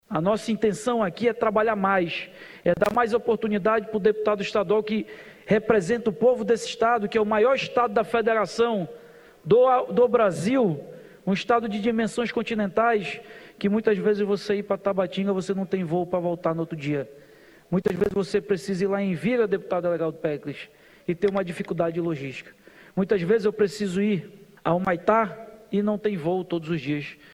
A medida, é justificada pelo presidente da Aleam, como uma forma de se adequar a logística do estado: